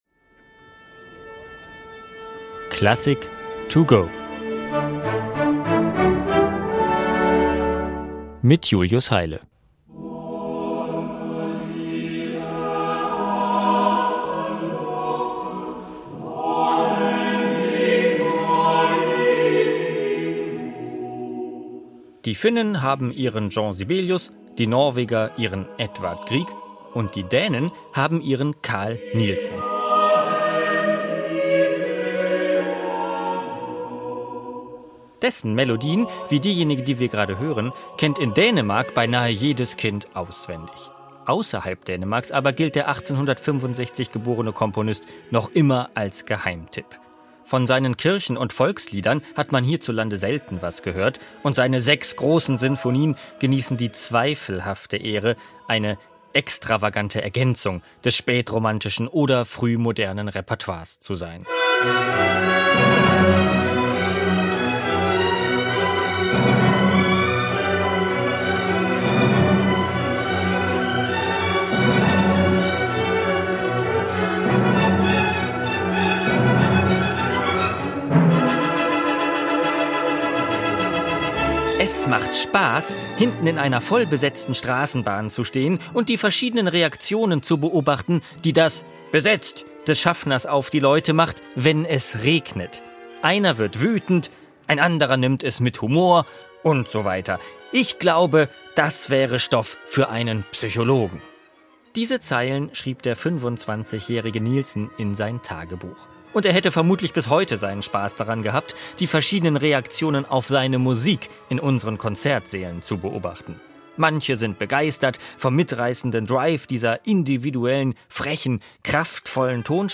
Kurzeinführung für unterwegs.